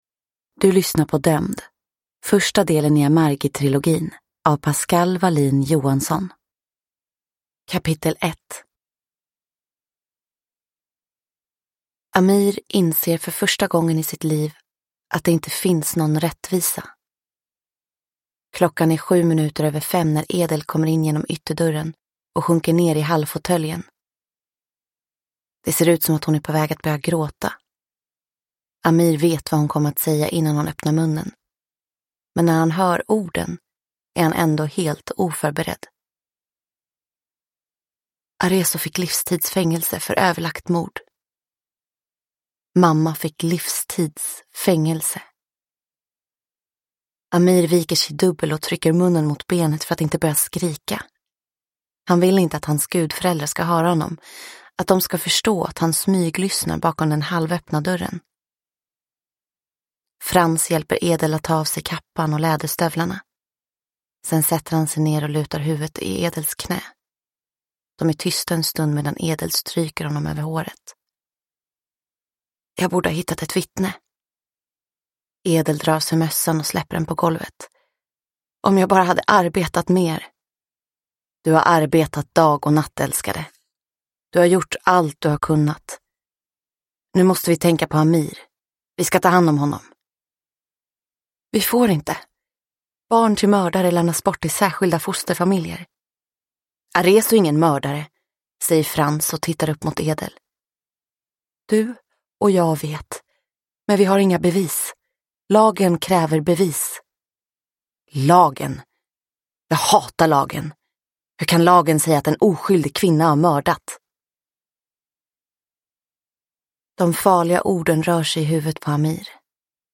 Dömd – Ljudbok